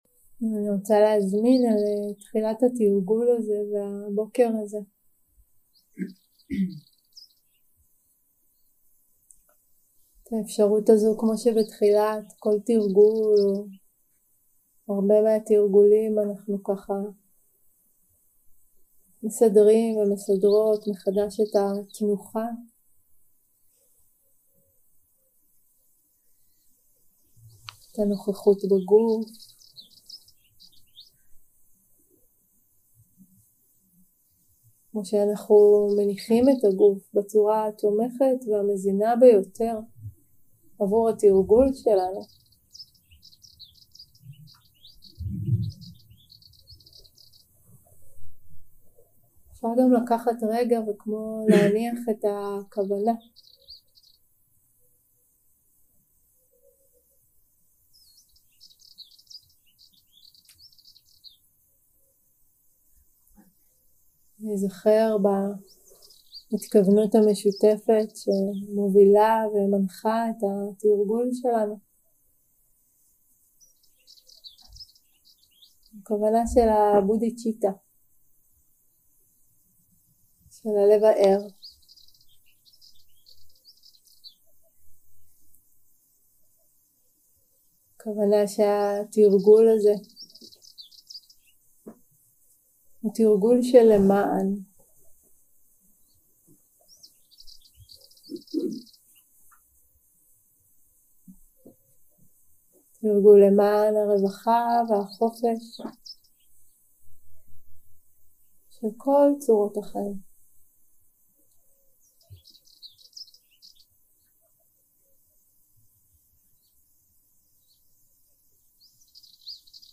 יום 4 – הקלטה 7 – בוקר – הנחיות למדיטציה – הנחיות לסמאדהי 0:00 0:00 סוג ההקלטה: Dharma type: Guided meditation שפת ההקלטה: Dharma talk language: Hebrew